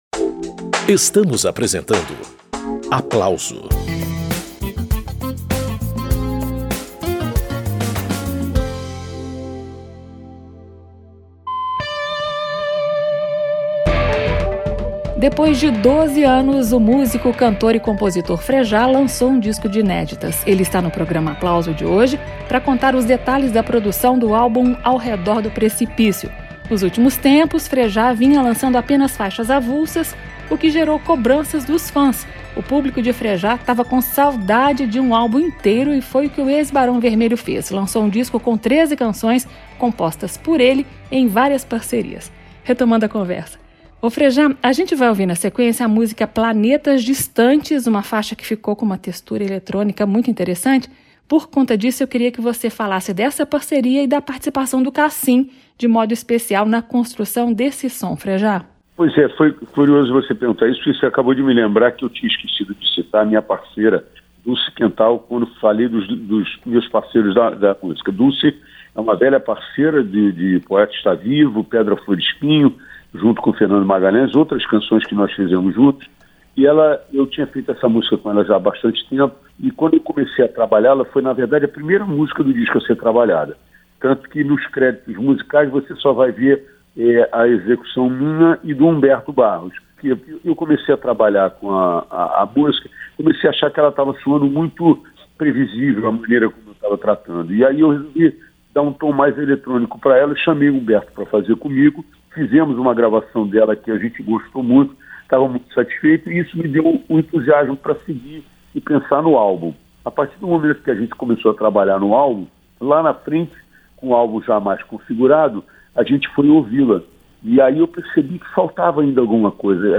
Depois de 12 anos, o cantor e compositor Frejat lança disco de inéditas. Ele participa desta edição do programa Aplauso para contar os detalhes da produção do álbum Ao Redor do Precipício.